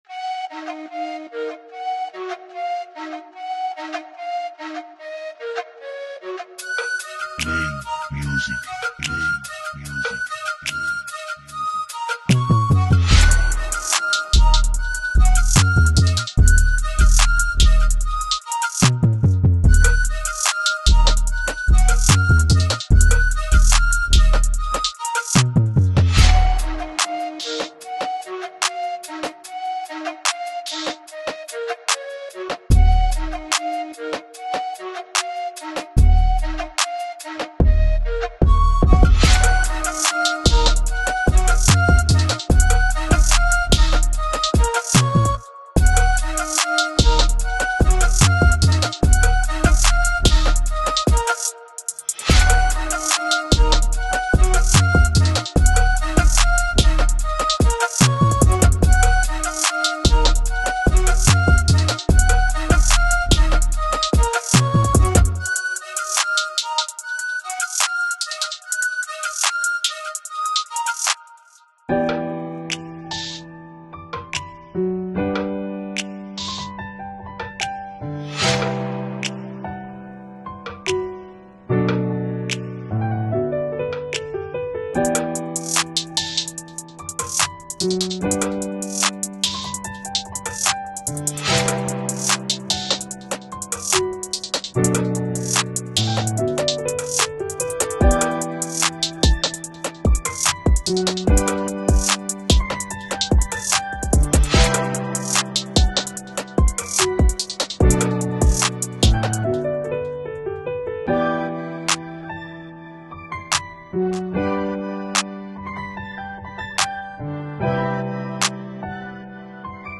Dîner débat à l’Unesco
Retour sur un moment fort de notre événement autour de Beate Klarsfeld à l’Unesco: